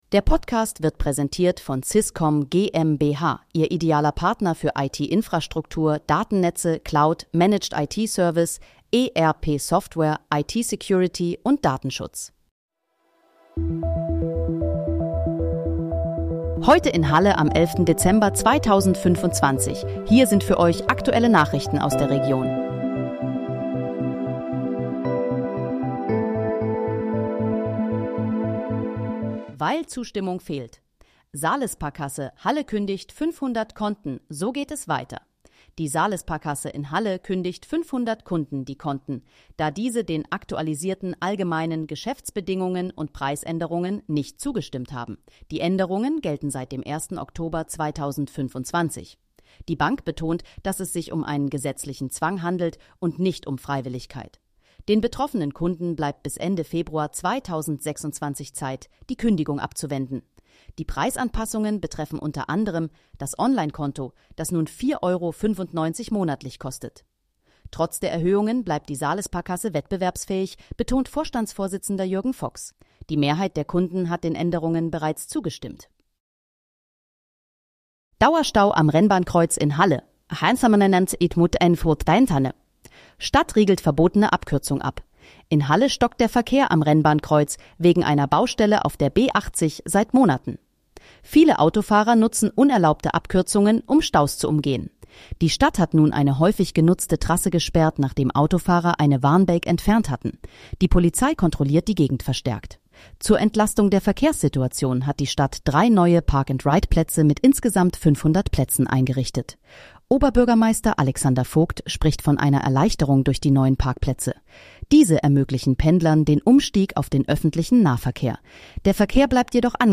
Heute in, Halle: Aktuelle Nachrichten vom 11.12.2025, erstellt mit KI-Unterstützung
Nachrichten